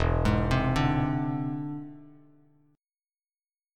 E+7 Chord
Listen to E+7 strummed